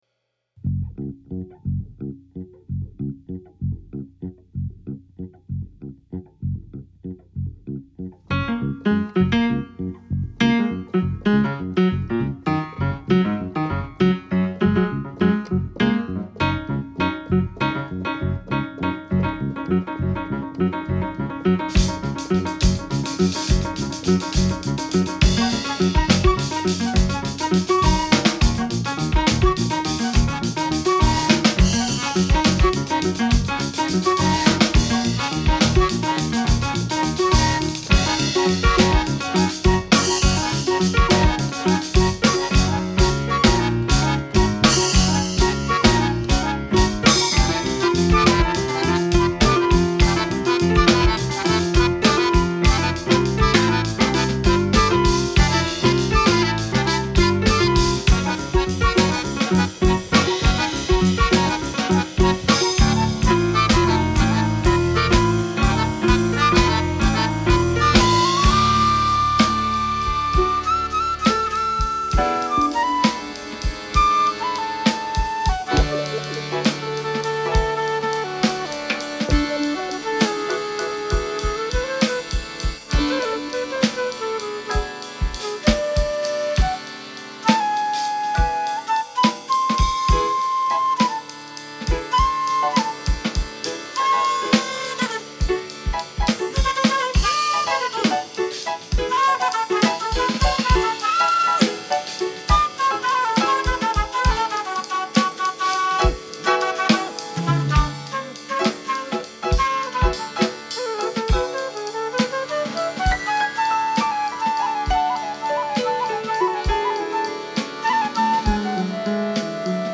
música experimental